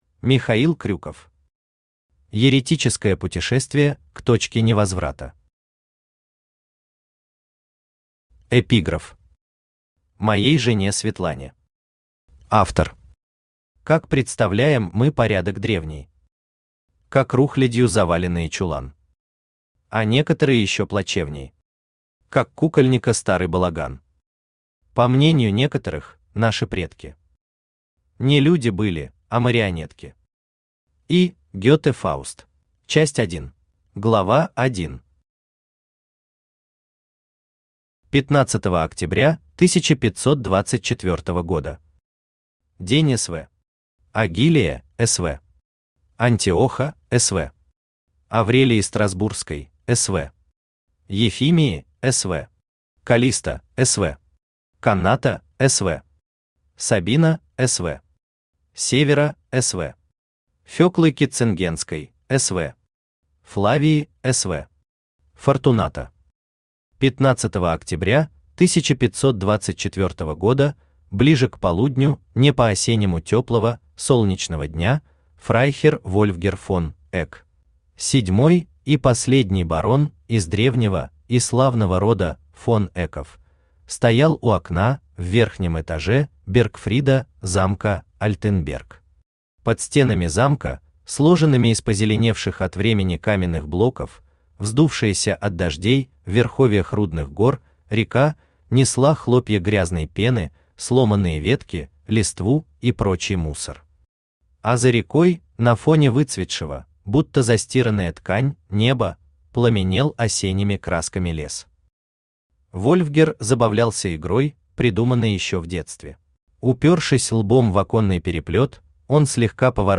Аудиокнига Еретическое путешествие к точке невозврата | Библиотека аудиокниг
Aудиокнига Еретическое путешествие к точке невозврата Автор Михаил Крюков Читает аудиокнигу Авточтец ЛитРес.